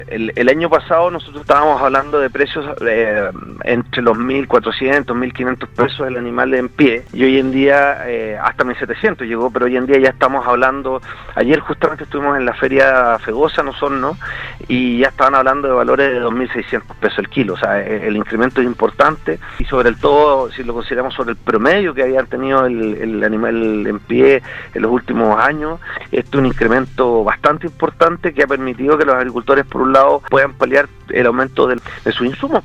En conversación con Radio Sago, Eduardo Winkler, Seremi de Agricultura de la región de Los Lagos, sostuvo que la carne viene con un alza constante en su precio durante este 2021.